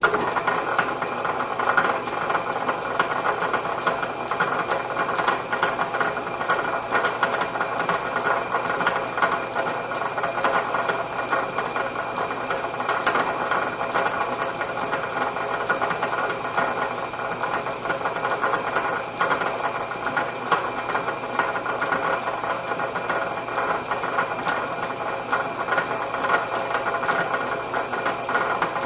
Gears Lathe With Leather Belt Pulley Rattle Clicks, Low